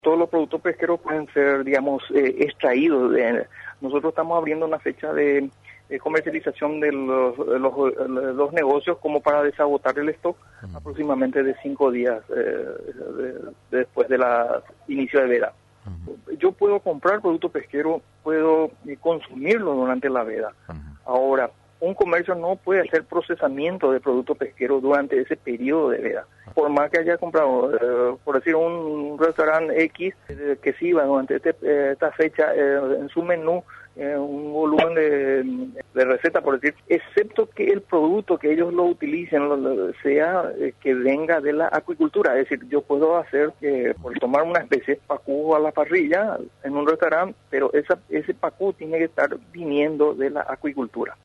El director de Biodiversidad del Ministerio del Ambiente y Desarrollo Sostenible, Darío Mandelburger, explicó que todos los productos pesqueros pueden ser comercializados hasta cinco días después de iniciar la veda para agotar el almacenamiento.